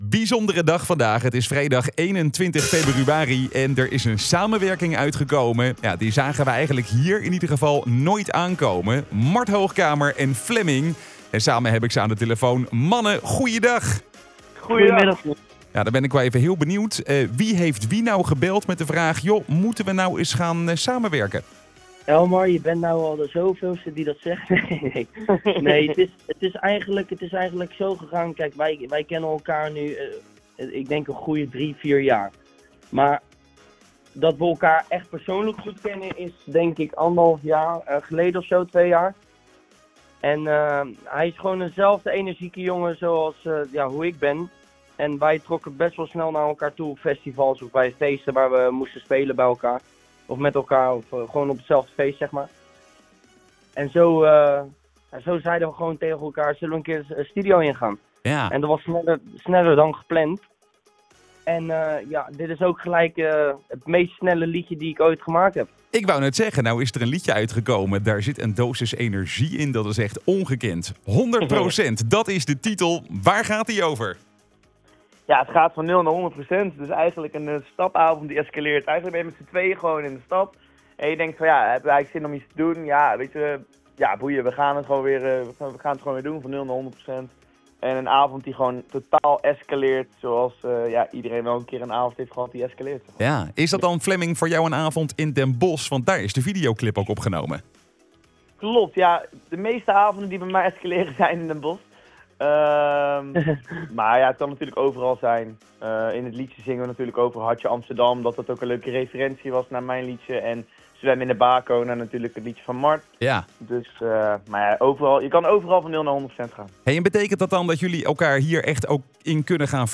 Interview_Mart_en_Flemming_over_de_single_100.wav